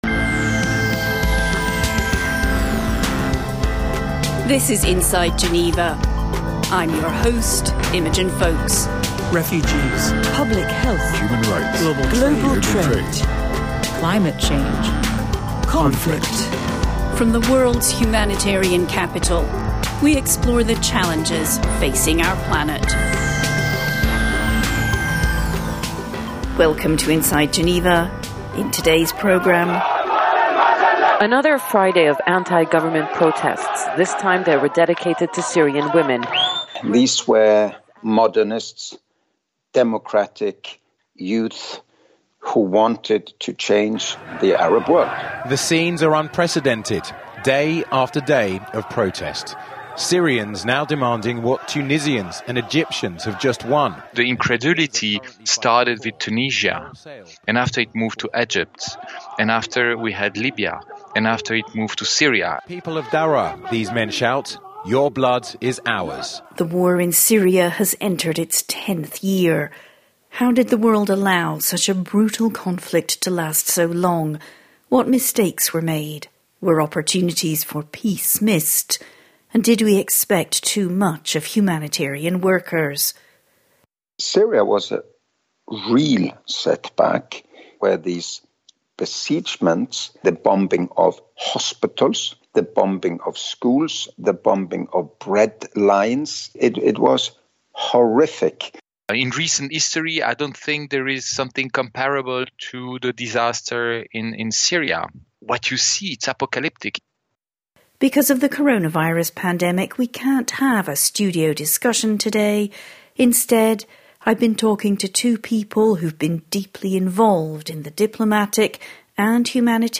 MP3 file Description people talking In this episode of Inside Geneva , we take a look at the ongoing conflict in Syria.